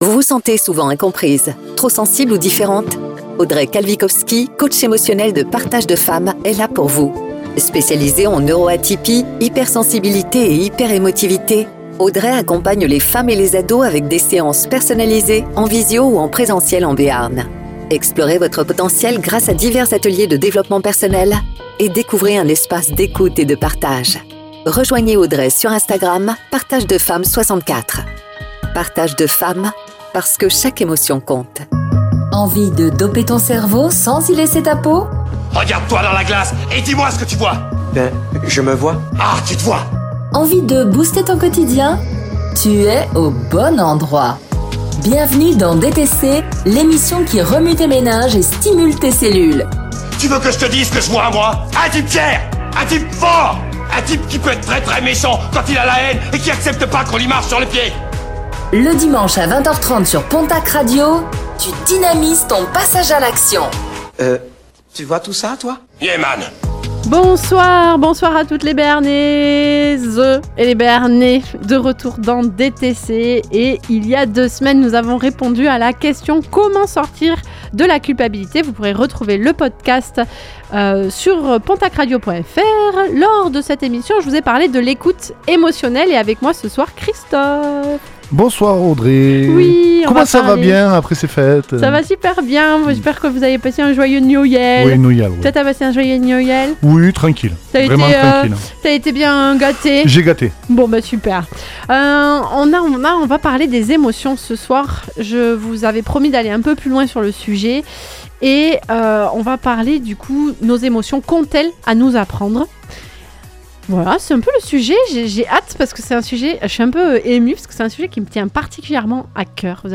Réécoutez l'émission DTC : « Nos émotions : qu'ont-elles à nous apprendre ?